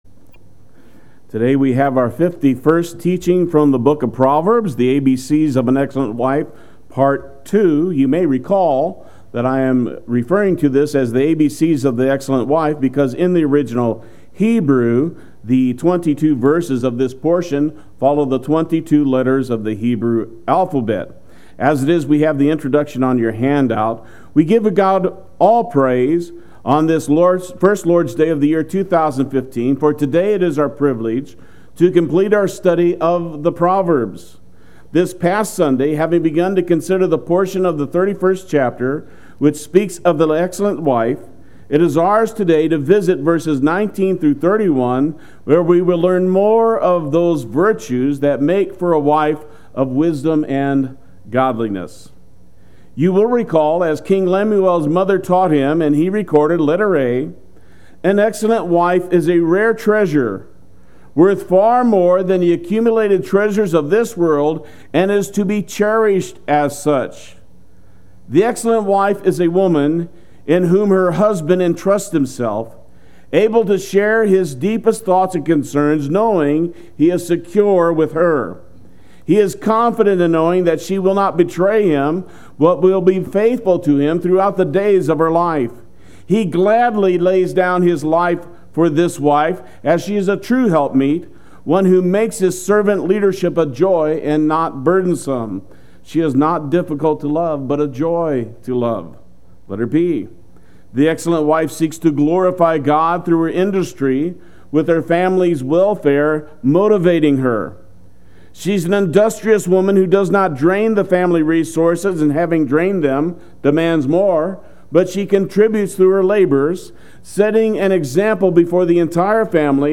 Play Sermon Get HCF Teaching Automatically.
” Part II Sunday Worship